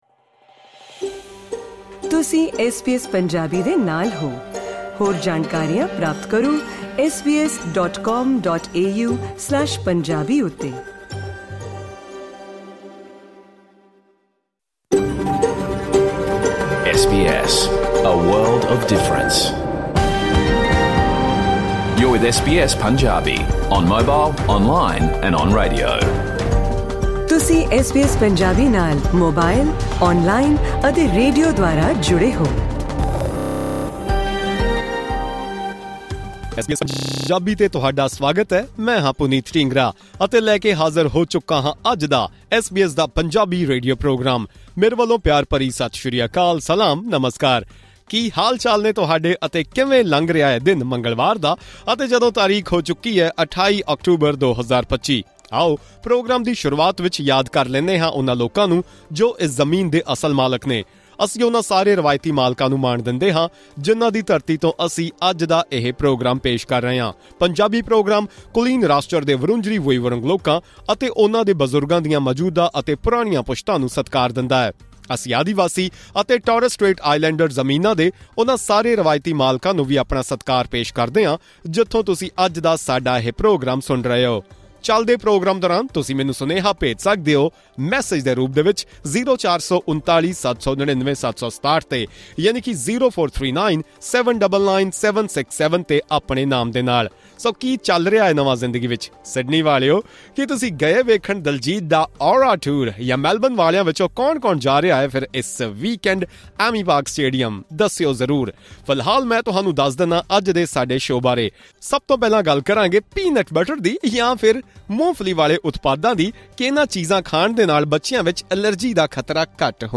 In this SBS Punjabi radio program, we discuss a survey that found feeding peanut products to young children can help prevent life-threatening allergies. Along with that, the show also features Australian and international news, as well as updates from both East and West Punjab.